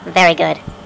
Very Good (cartoon) Sound Effect
very-good-cartoon.mp3